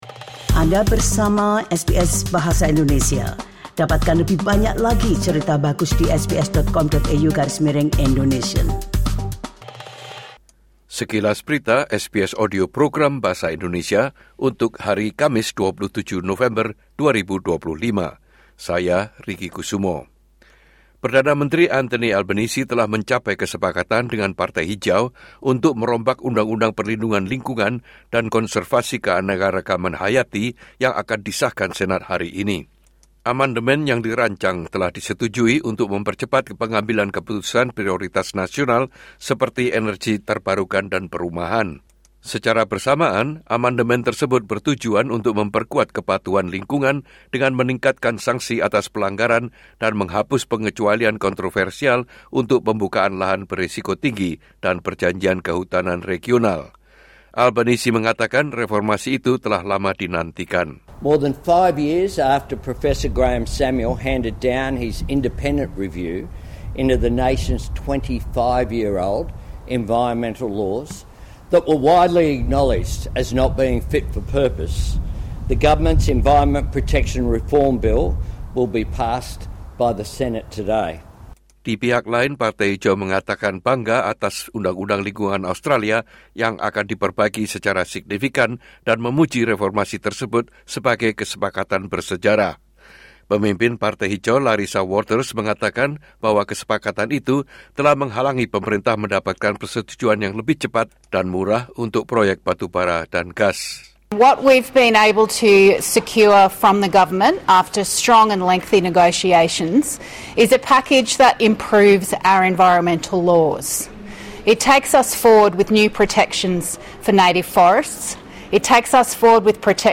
Latest News SBS Audio Program Bahasa Indonesia - Thursday 27 November 2025